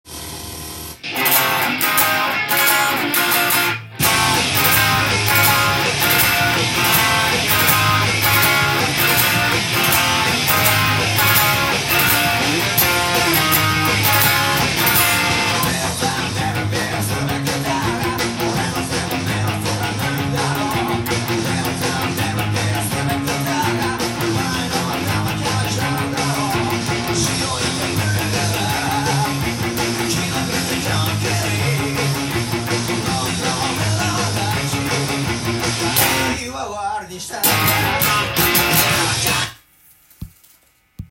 熱いガレージロックの代表的バンドです。
エレキギターTAB譜
音源に合わせて譜面通り弾いてみました
何度も高速16分音符が出てきます。
オクターブ奏法がイントロの終わりの方に出てきます。